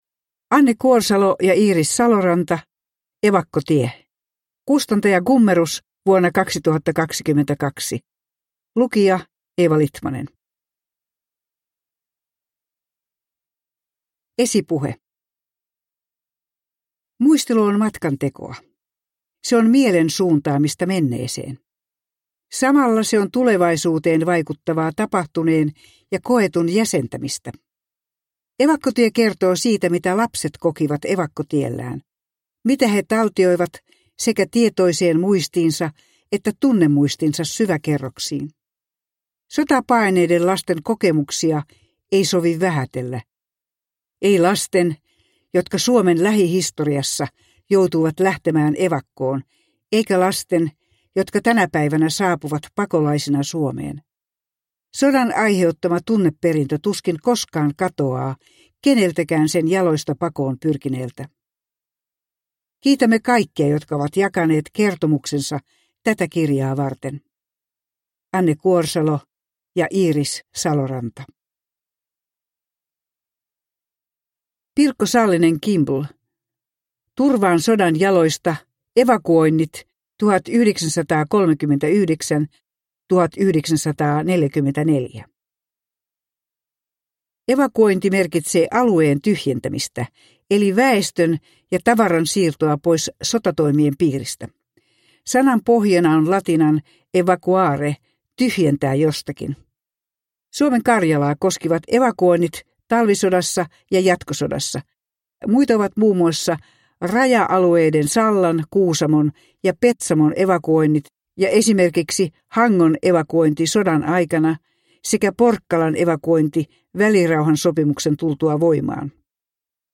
Evakkotie – Ljudbok